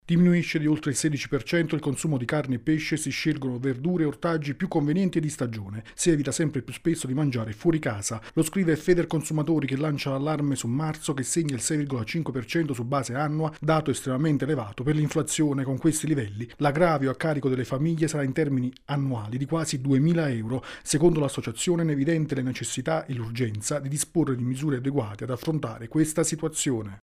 Vita cara: Federconsumatori lancia l’allarme inflazione che colpisce le famiglie dopo i dati di marzo. Il servizio